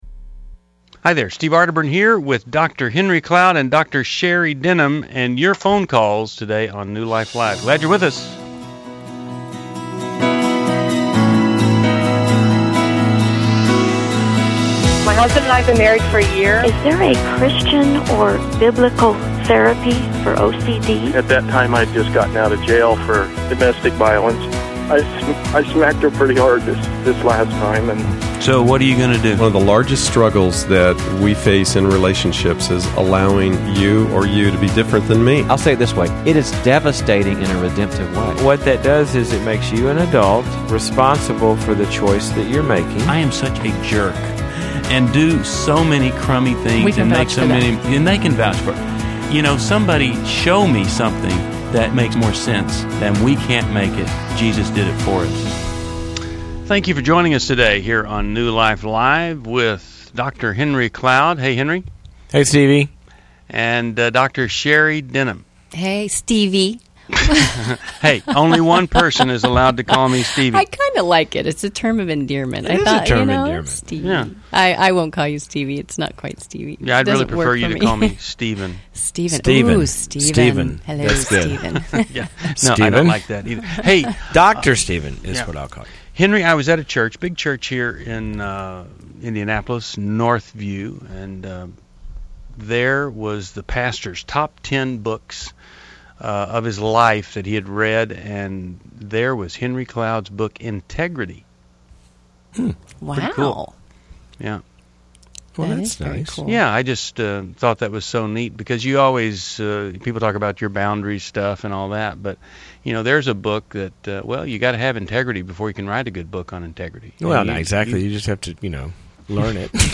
Explore weight loss, anxiety, motivation, and boundaries in New Life Live: October 12, 2011, as experts tackle caller concerns and provide practical insights.